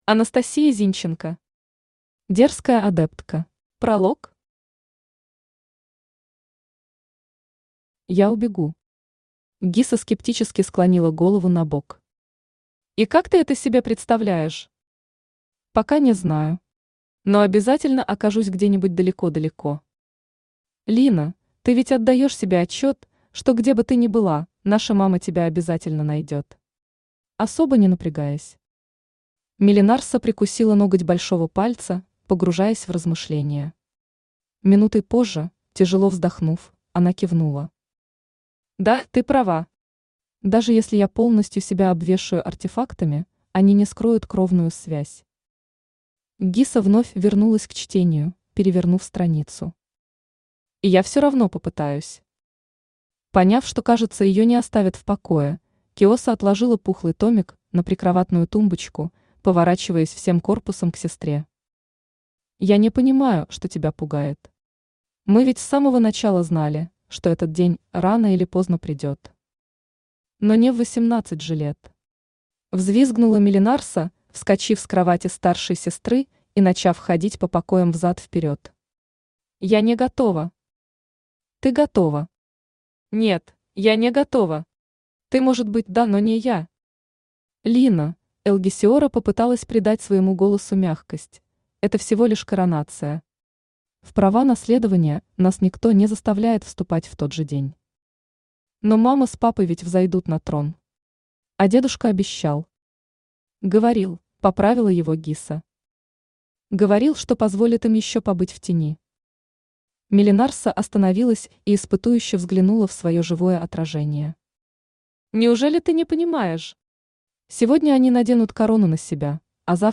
Аудиокнига Дерзкая адептка | Библиотека аудиокниг
Aудиокнига Дерзкая адептка Автор Анастасия Зинченко Читает аудиокнигу Авточтец ЛитРес.